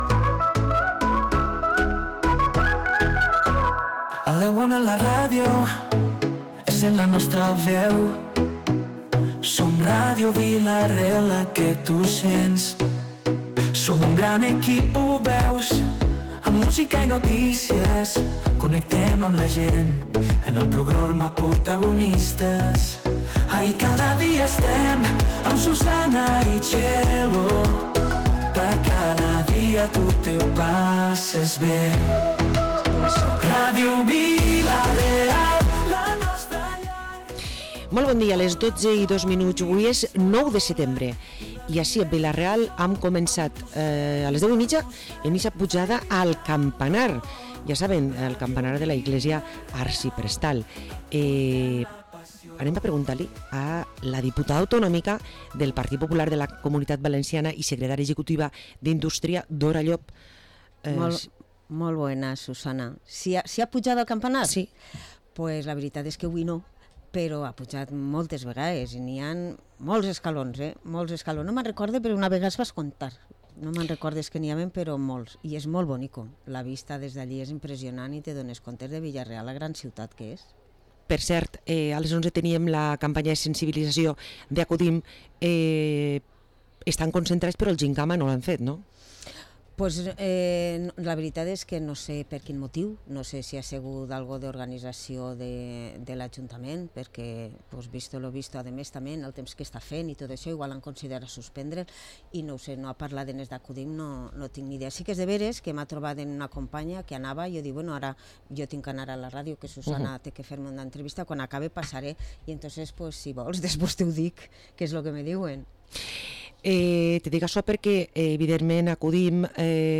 Parlem amb la Diputada Autonòmica, Dora Llop